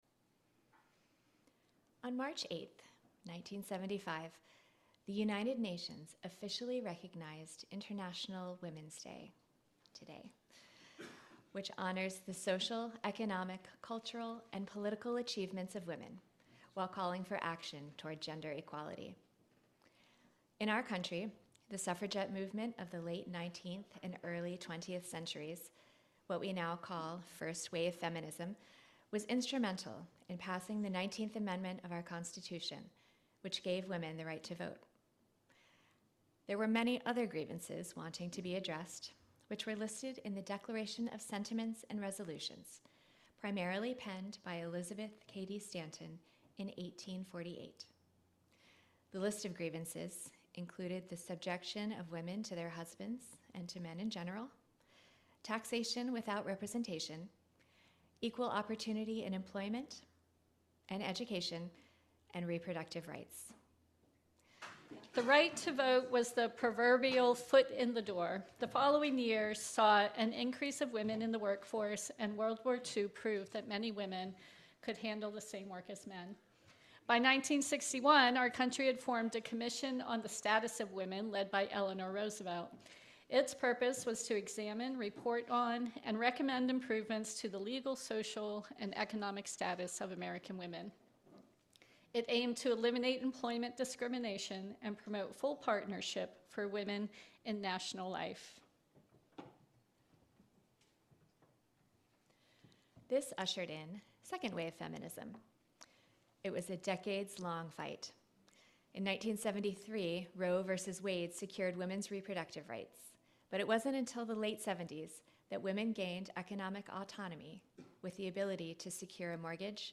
This sermon chronicles the evolution of feminist movements, tracing a path from the historical fight for legal and economic rights to a modern focus on inclusivity and intersectionality.